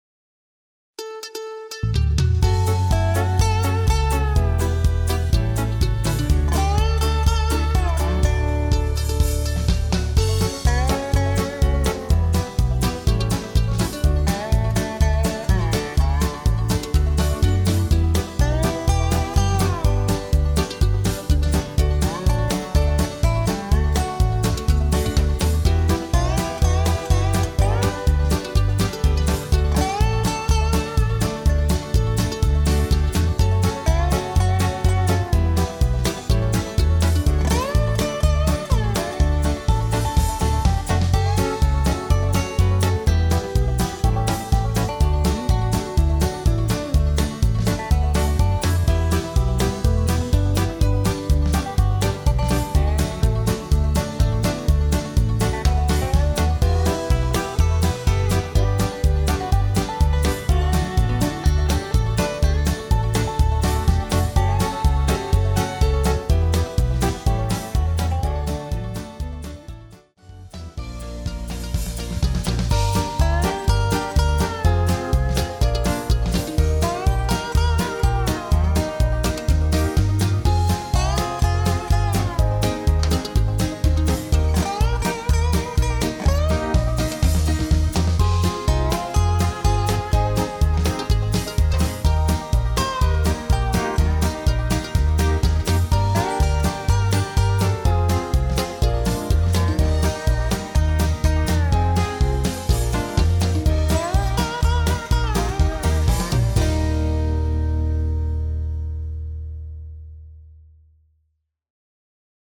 Instrumental TRacks